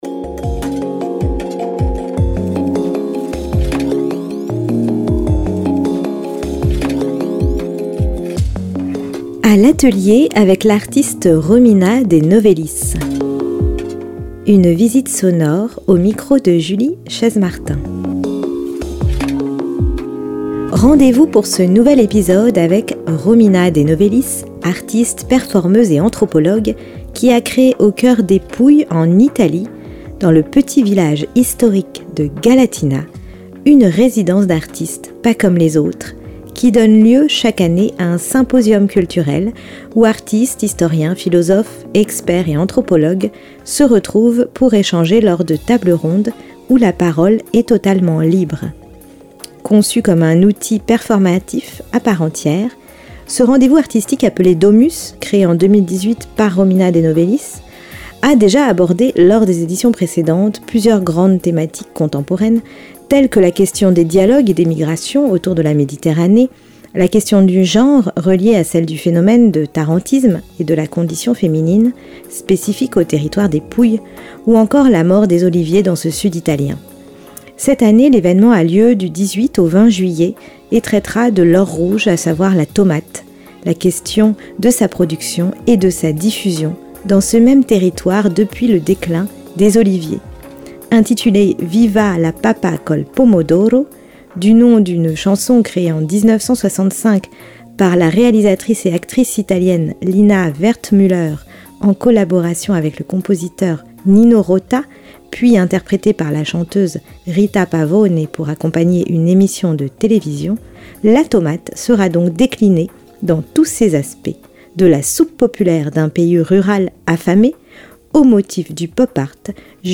ART INTERVIEW, A L'ATELIER lundi et jeudi à 14h.